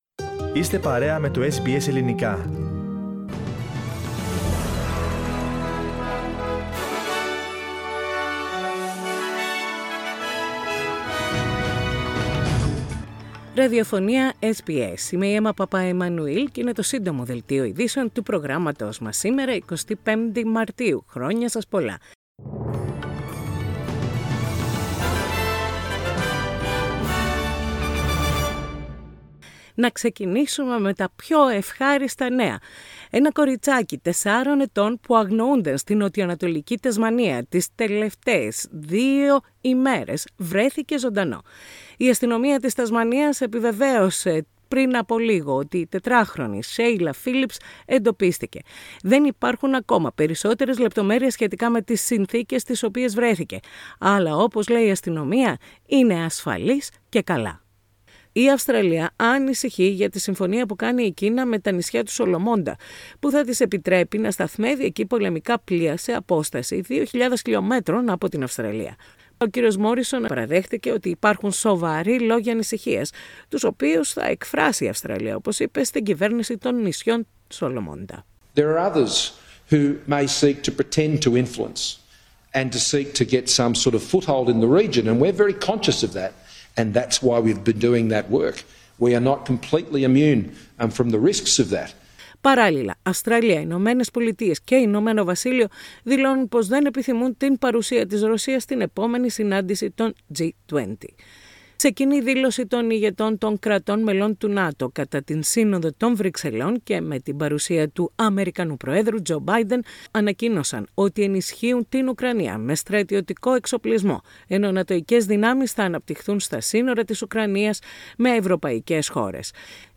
Δελτίο Ειδήσεων - Παρασκευή 25η Μαρτίου 2022
News in Greek.